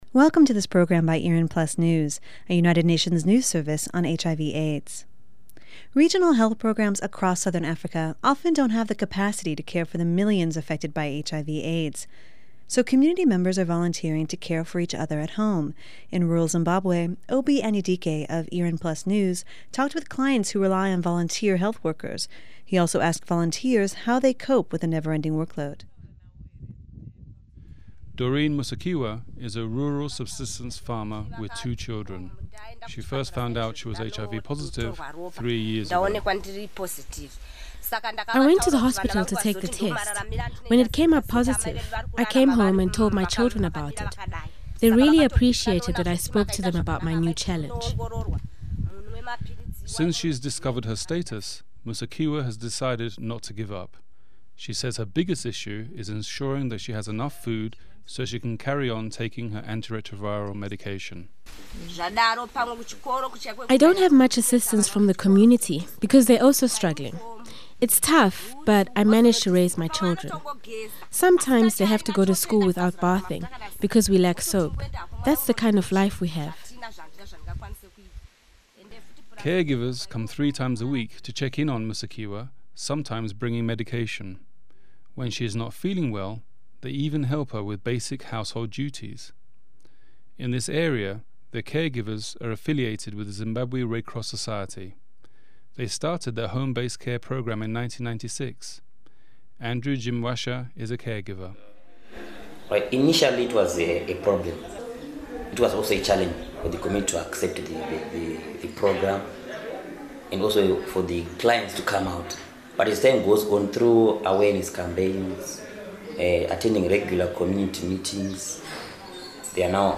IRIN/PlusNews spoke to caregivers in Zimbabwe about how they cope with the neverending workload.